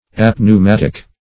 Search Result for " apneumatic" : The Collaborative International Dictionary of English v.0.48: Apneumatic \Ap`neu*mat"ic\, a. [Gr.